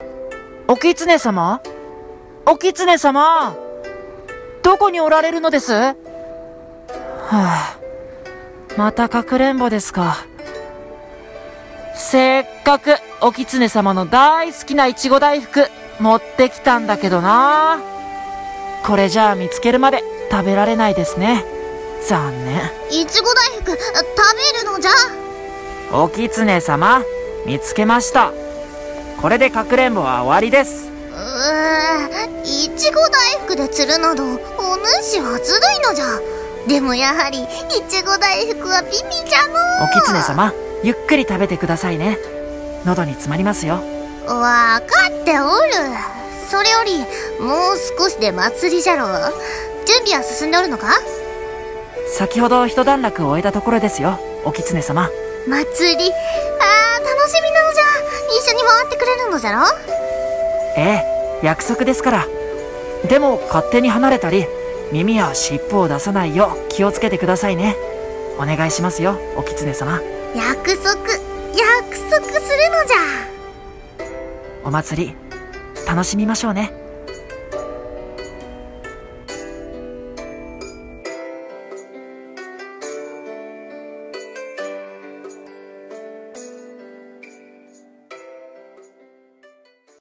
【声劇】
【掛け合い】